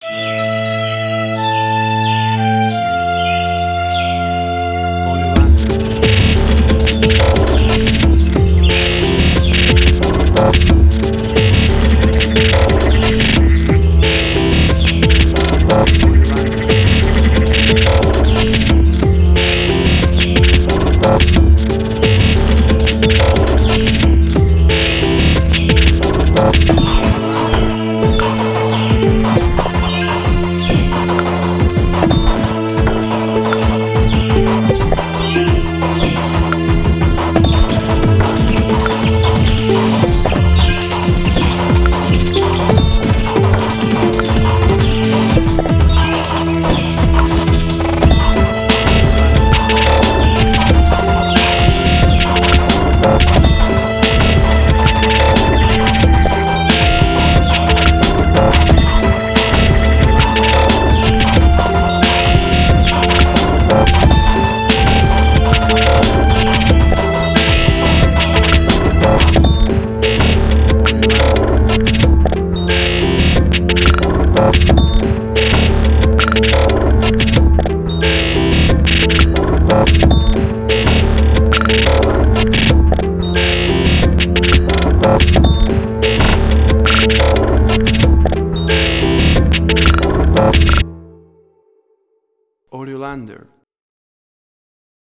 IDM, Glitch.
emotional music
Tempo (BPM): 90